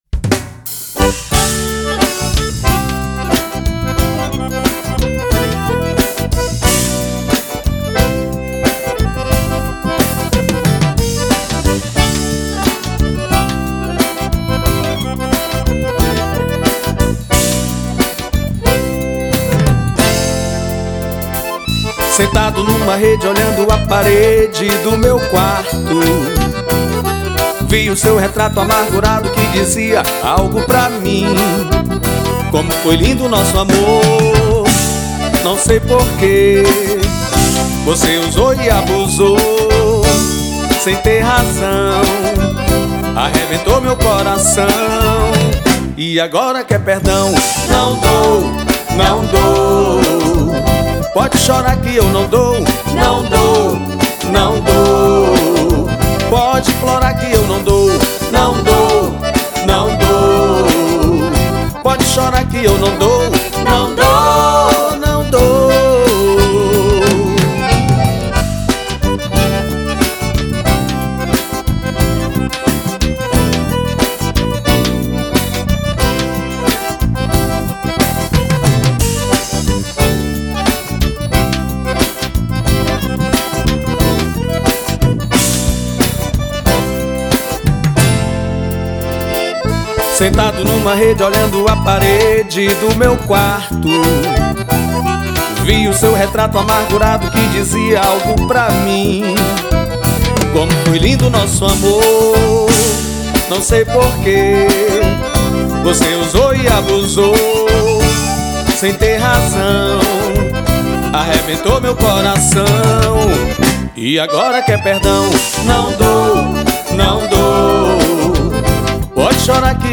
2523   02:40:00   Faixa: 2    Baião